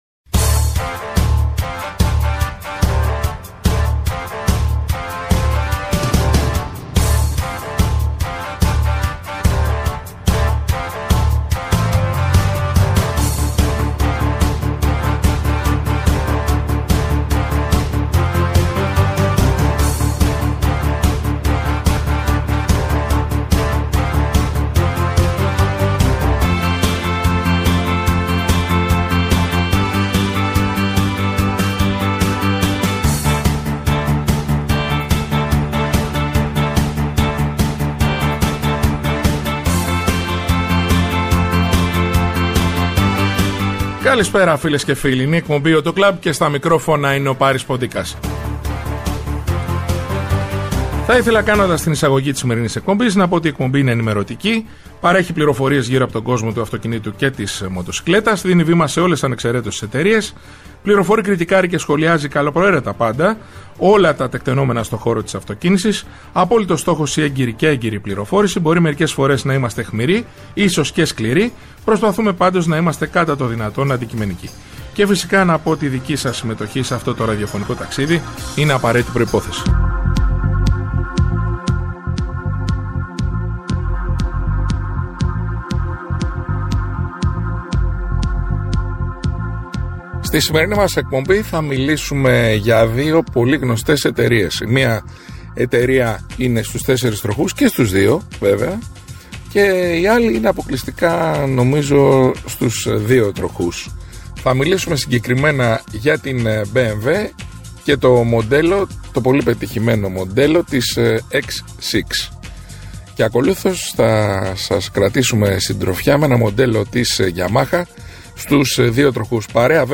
Η εκπομπή «AUTO CLUB» είναι ενημερωτική, παρέχει πληροφορίες γύρω από τον κόσμο του αυτοκινήτου και της μοτοσικλέτας, δίνει βήμα σε όλες ανεξαιρέτως τις εταιρείες, φιλοξενεί στο στούντιο ή τηλεφωνικά στελέχη της αγοράς, δημοσιογράφους αλλά και ανθρώπους του χώρου. Κριτικάρει και σχολιάζει καλοπροαίρετα πάντα όλα τα τεκταινόμενα στο χώρο της αυτοκίνησης, με απόλυτο στόχο την έγκαιρη και έγκυρη πληροφόρηση για τους ακροατές, με «όπλο» την καλή μουσική και το χιούμορ. Περιλαμβάνει τις εξής ενότητες: -Παρουσίαση νέων μοντέλων αυτοκινήτου, μοτοσικλέτας, σκάφους.